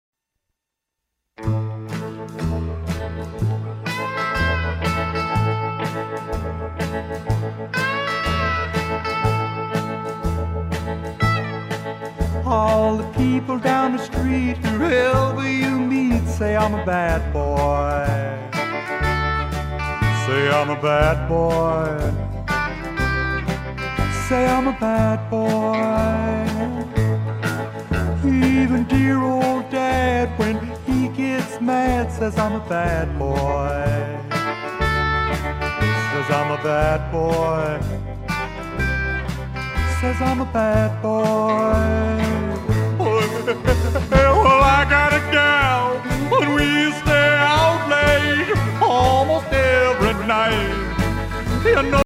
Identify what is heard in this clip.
Genre: Rockabilly